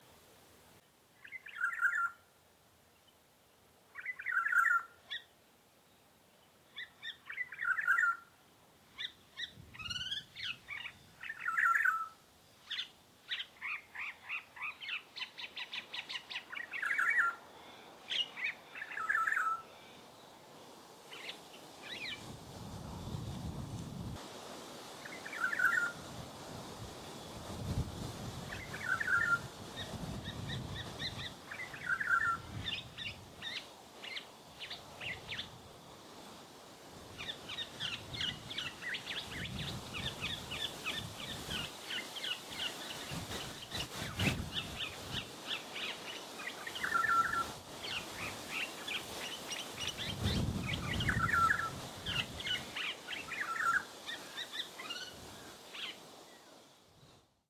For about half an hour it warbled away with a mix of its regular call and a sub-song that included mimicry. Unfortunately there was a strong wind blowing (when hasn’t a strong wind been blowing lately!) – not good for sound recording!
But in the audio files below we think, with a little imagination, we can hear snippets of the calls of Rufous Whistler, Yellow-tailed Black Cockatoo, Common Blackbird, Sacred Kingfisher (the lovely trill) and perhaps others we haven’t identified. The only other bird close by was a Yellow-faced Honeyeater, whose call can be clearly heard, but the oriole seemed to be trying to mimic that too.
oriole-2.mp3